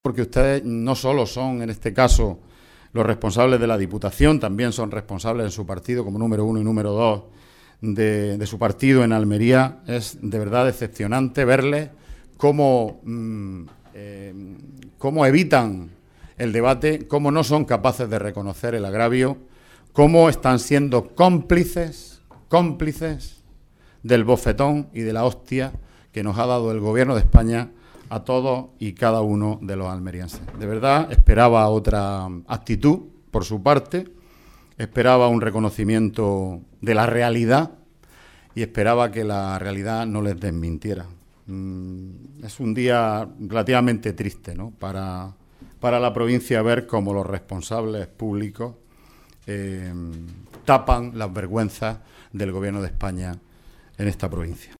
Grupo Socialista en el Pleno de la Diputación de Almería